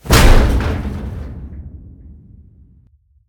metal3.ogg